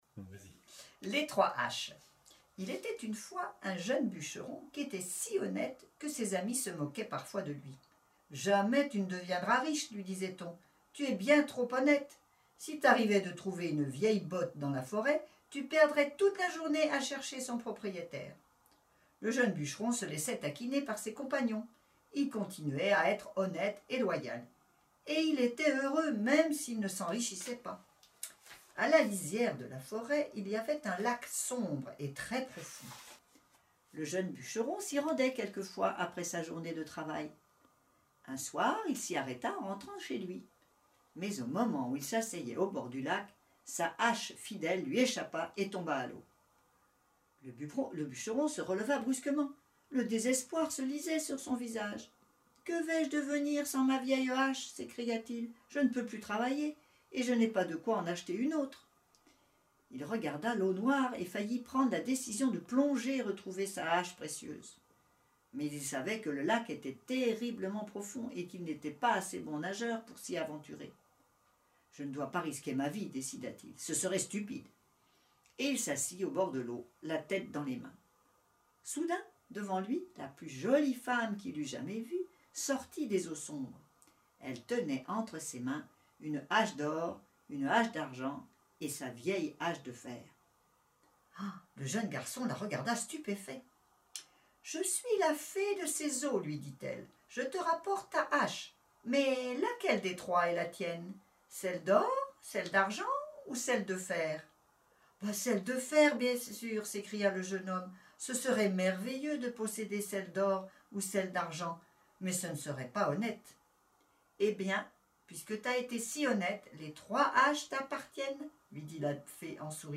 Une histoire racontée